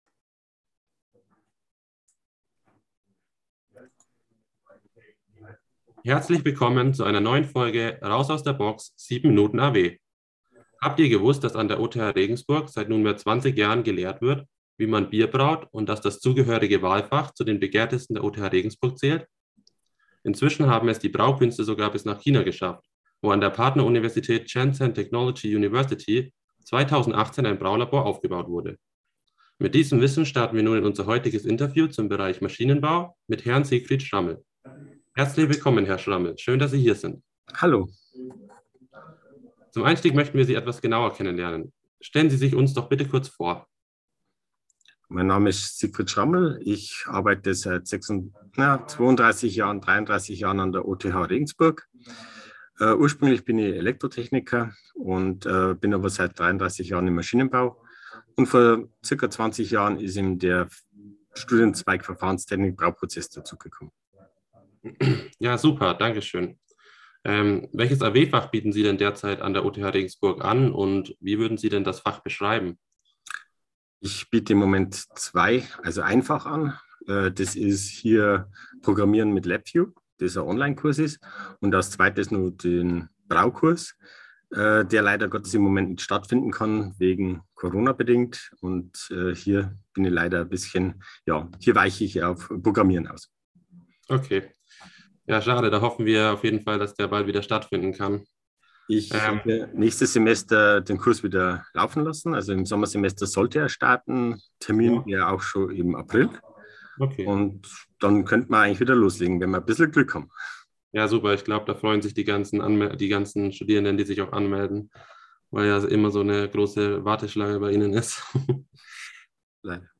Raus aus der Box - Interview 2021 zur AW-Kurs "Verfahrenstechnik Brauprozess"